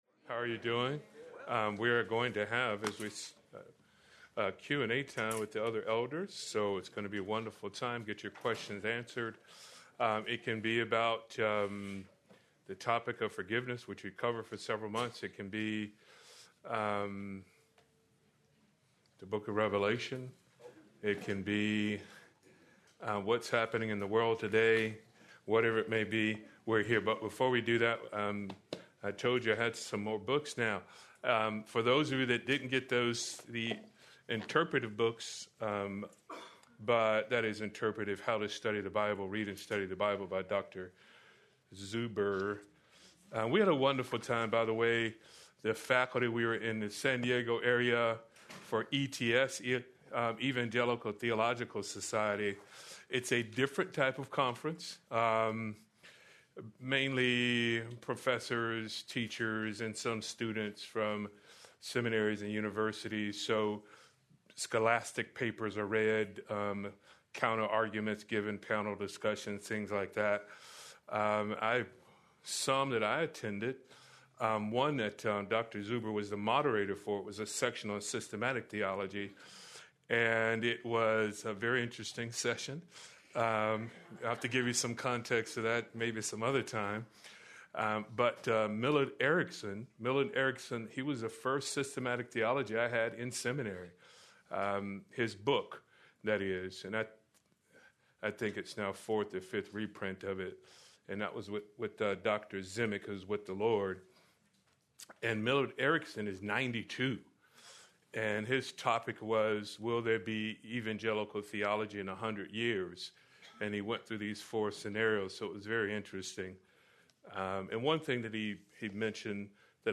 Q&A with Elders